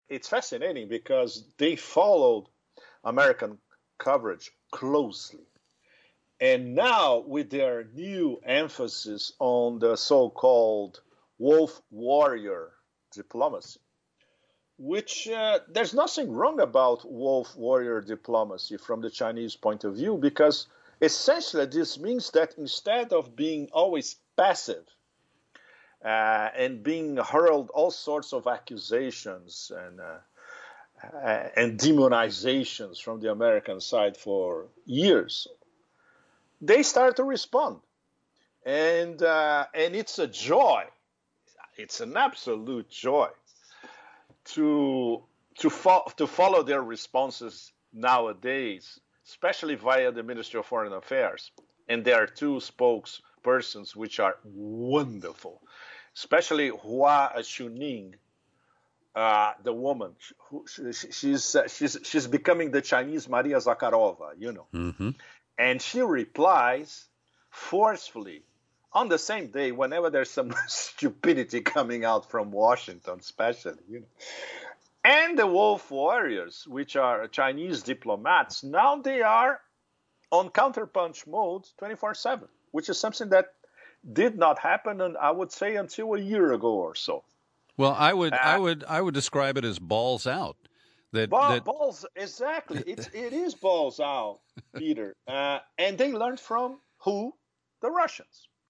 Globetrotting journalist Pepe Escobar joins us for a Last Interview, from Bangkok.